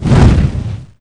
torchon1.wav